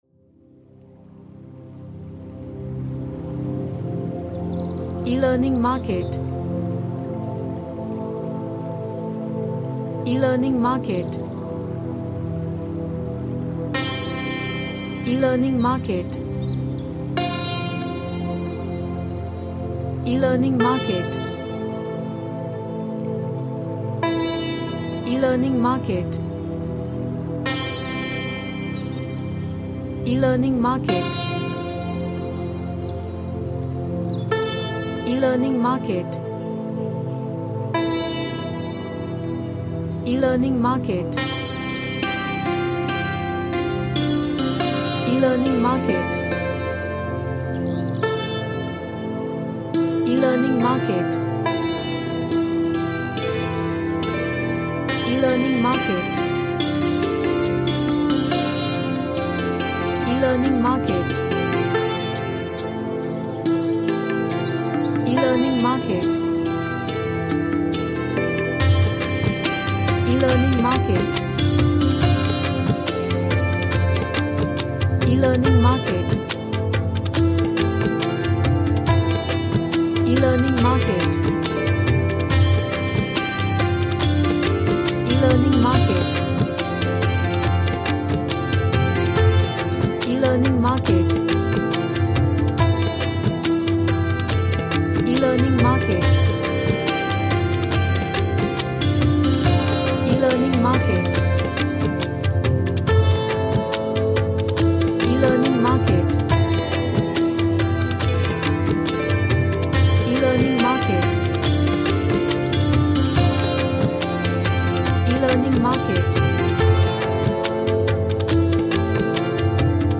Relaxation / Meditation